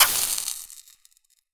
fireball_impact_sizzle_burn2.wav